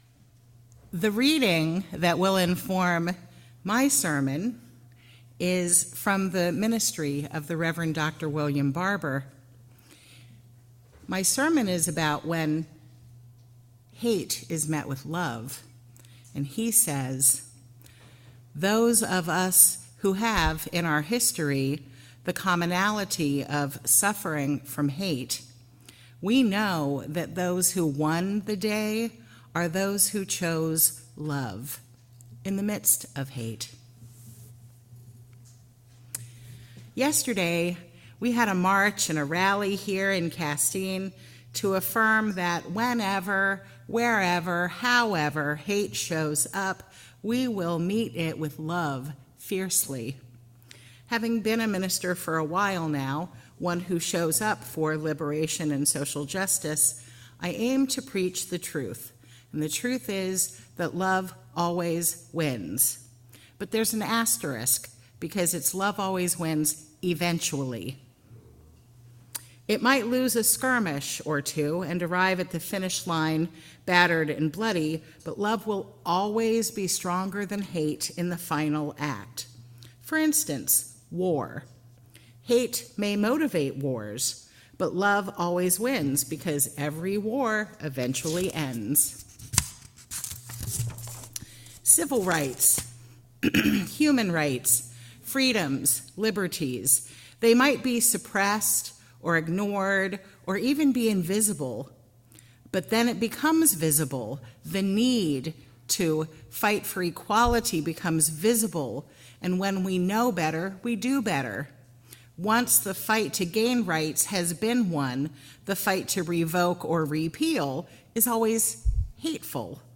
Sermon: Meeting Hate with Love. We use words like fight, or battle, but really the struggle we live with is how to act in love when it does not seem possible.